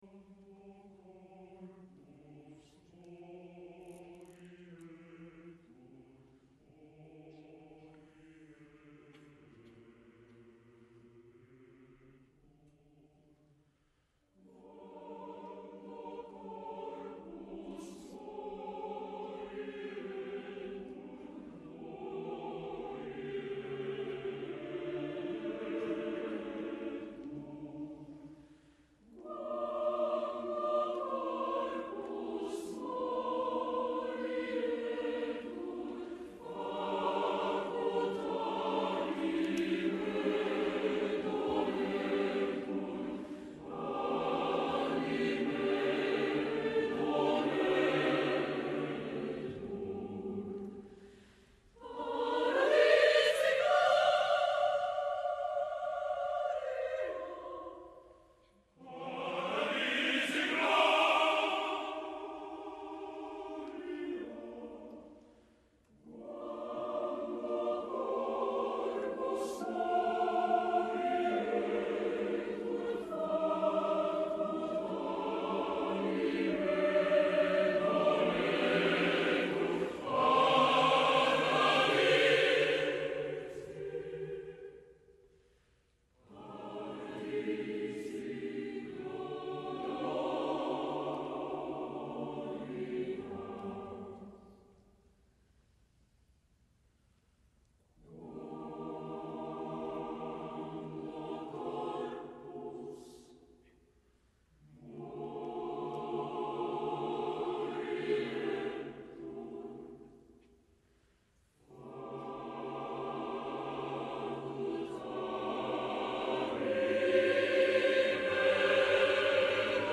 La primera versió del Stabat Mater de Gioachino Rossini, per a dues sopranos, tenor, baix, cor i orquestra es va interpretar per primer cop el 5 d'abril de 1833 a la capella de San Felipe el Real de Madrid.